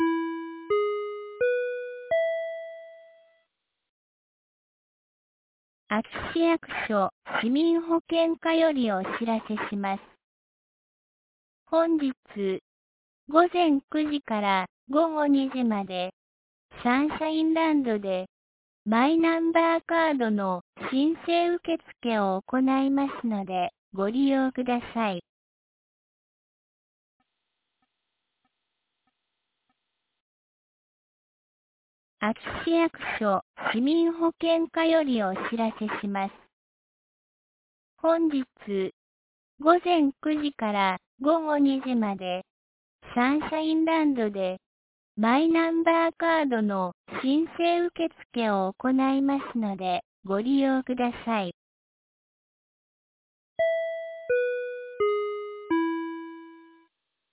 2024年10月19日 08時51分に、安芸市より全地区へ放送がありました。
放送音声